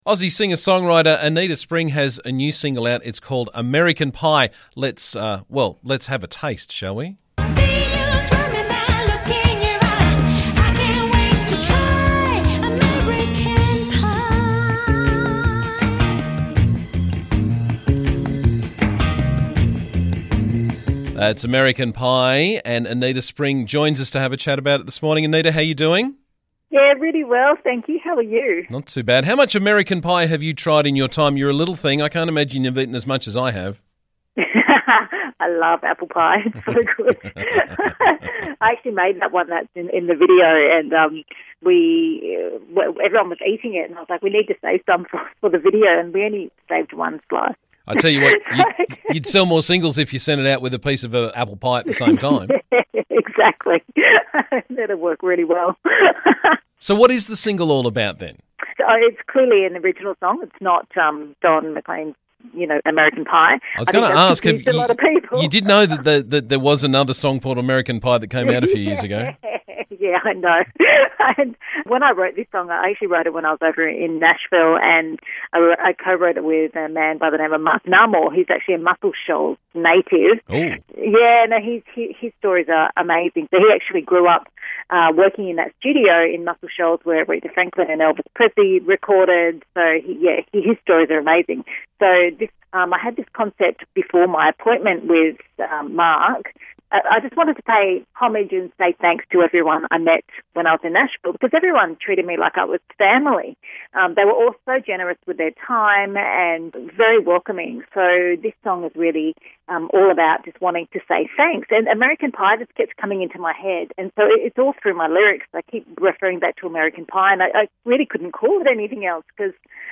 she joined me on the show this morning to tell us all about it.